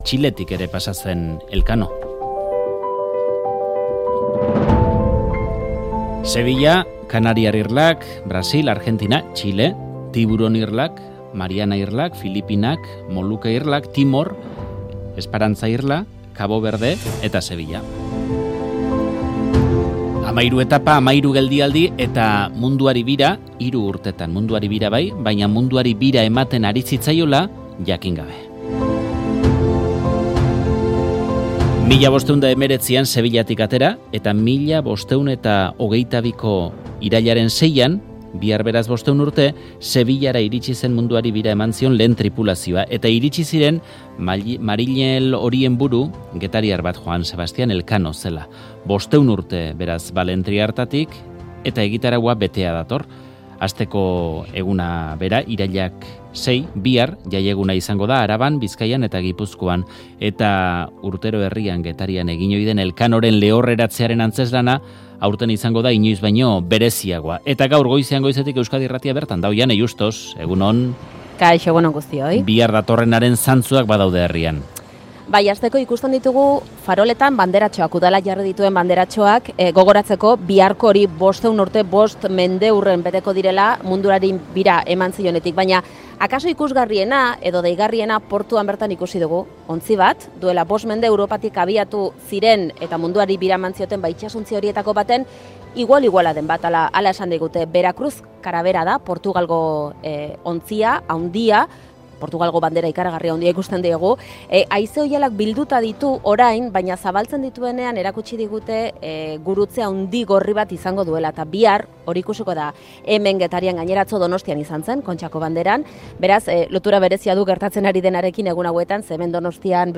Faktoria Getarian izan da, Elkanoren lehorreratzearen bezperan.